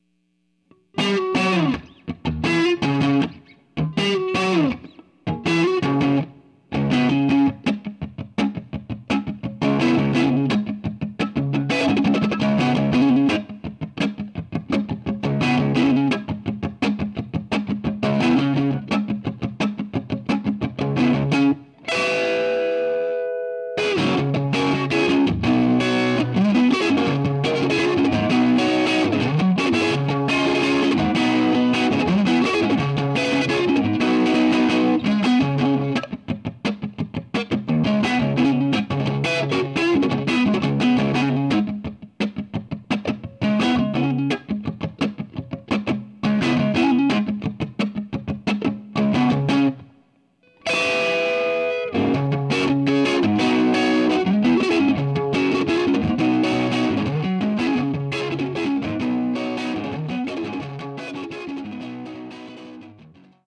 - Repro du JTM45/100 de 66 (version des débuts d'Hendrix avec Marshall)